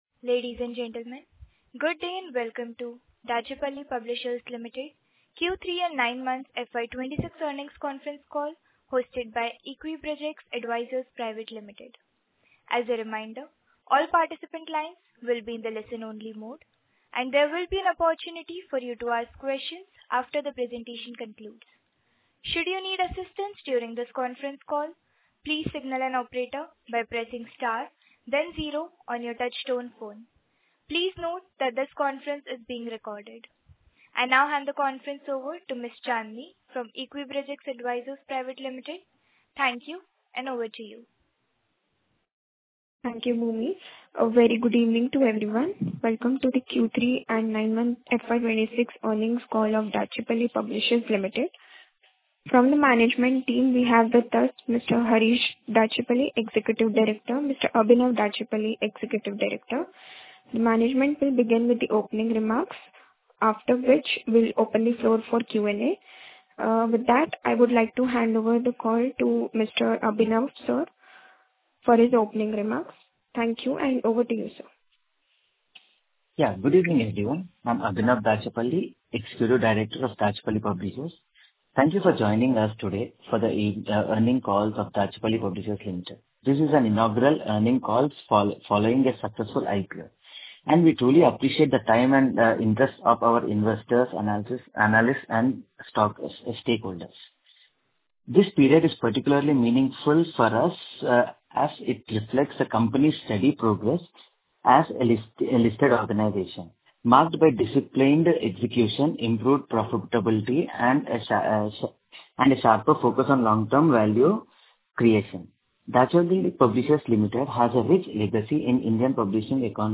Earnings_Call_Audio_Q3_&_9M_FY26.mp3